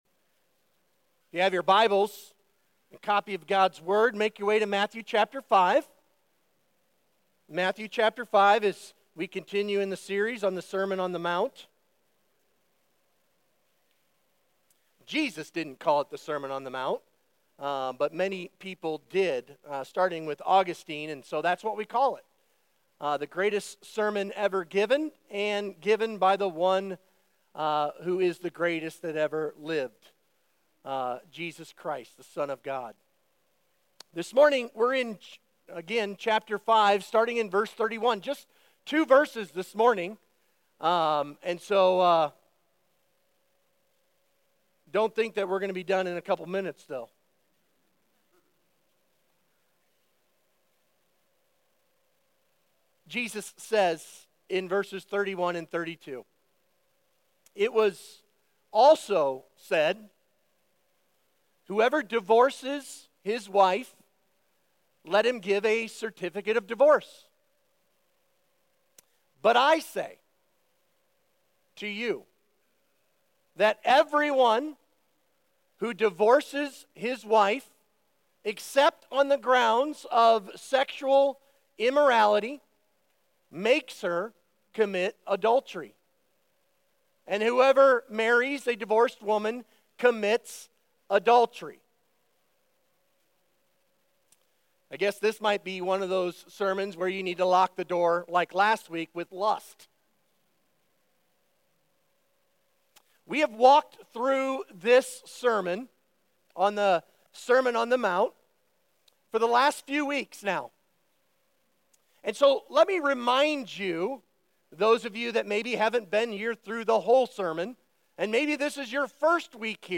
Sermon Questions Read Matthew 5:31-32